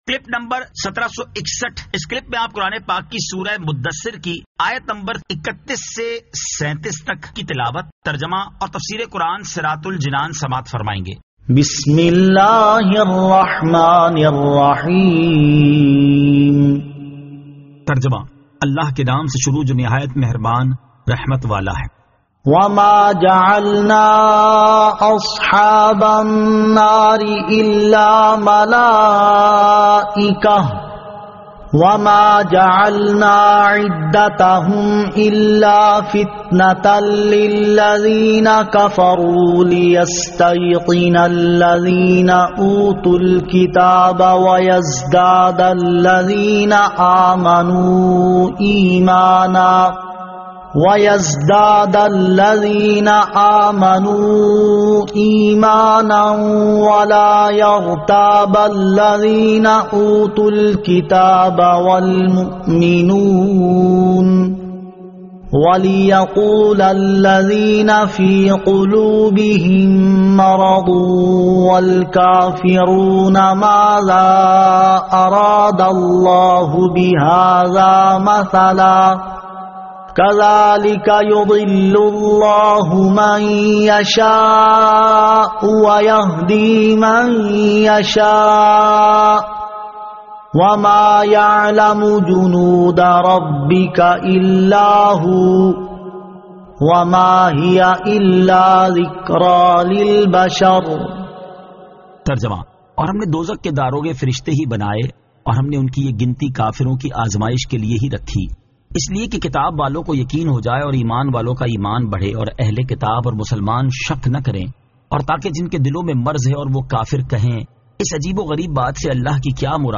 Surah Al-Muddaththir 31 To 37 Tilawat , Tarjama , Tafseer